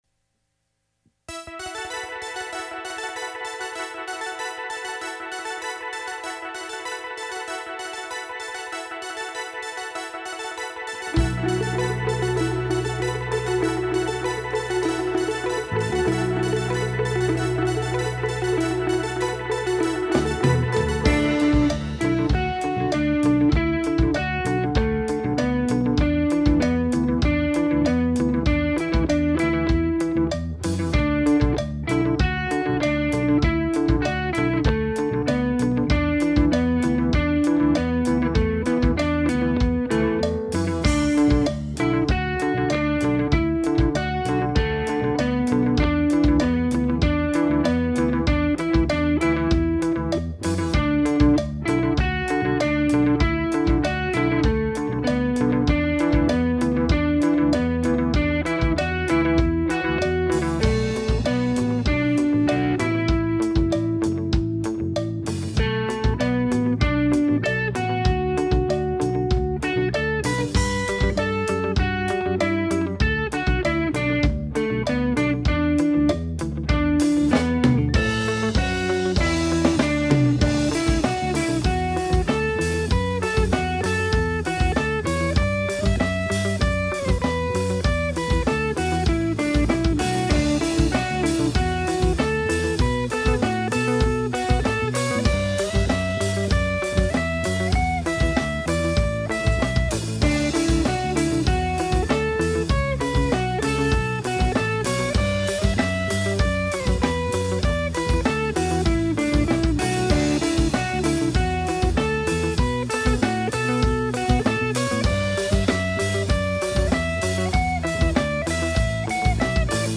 90-20’S ROCK
この哀愁！！